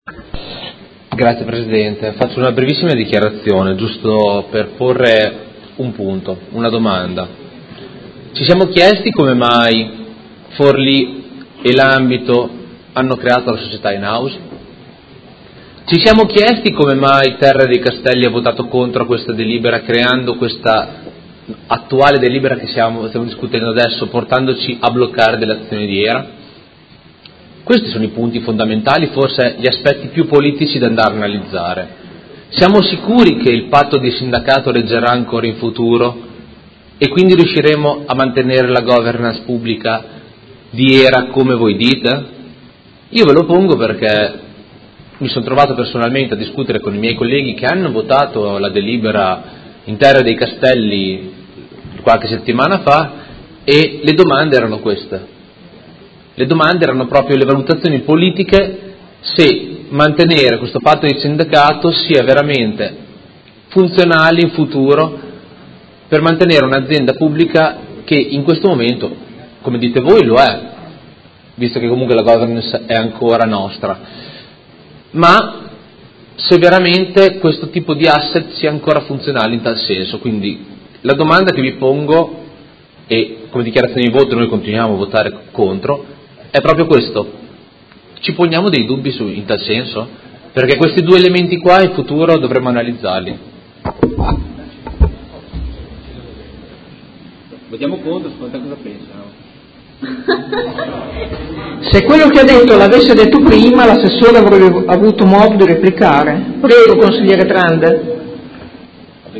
Seduta del 31/05/2018 Dichiarazione di voto.